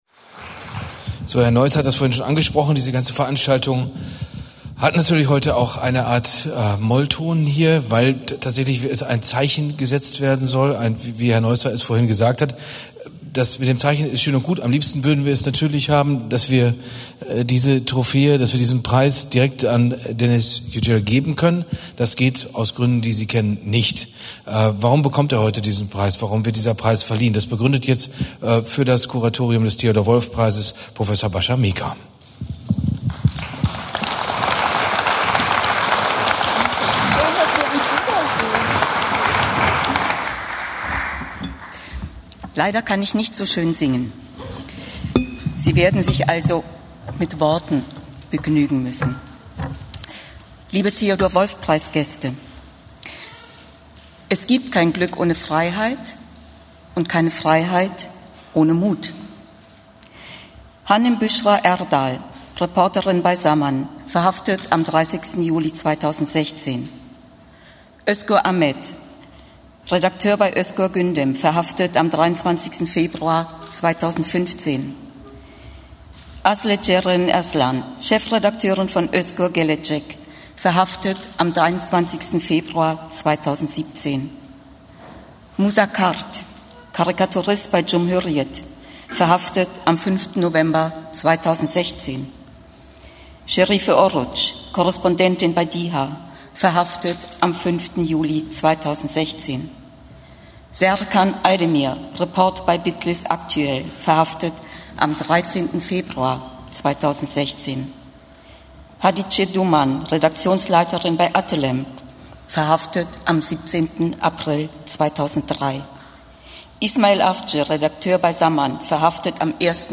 Was: Laudatio für den Theodor-Wolff-Preis/Sonderpreis
Wo: Radialsystem V, Berlin
Schauspieler
Was: Rede